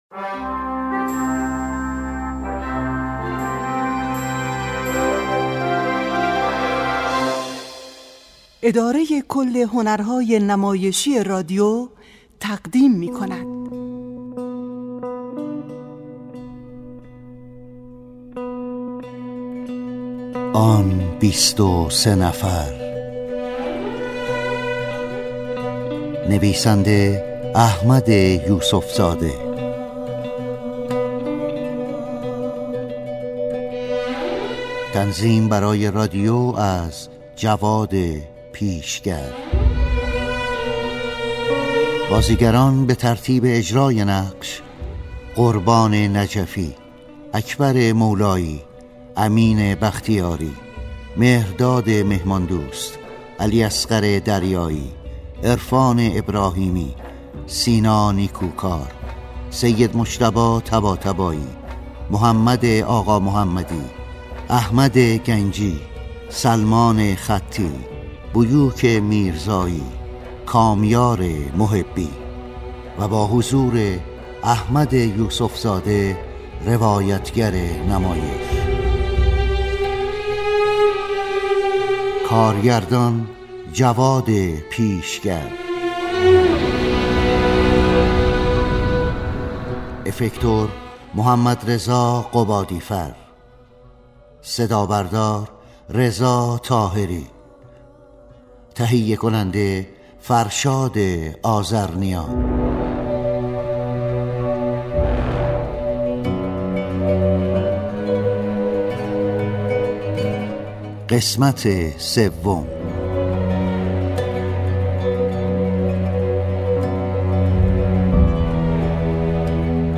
کلیپ صوتی/ اجرای بخش هایی از کتاب «آن بیست و سه نفر»
کتاب صوتی «آن بیست و سه نفر» اثر «احمد یوسف زاده» حاوی خاطرات بیست و سه نوجوان است که در عملیات بیت المقدس به اسارت درآمدند و صدام سعی داشت از این نوجوانان برای ایجاد جنگ روانی علیه ایران استفاده کند.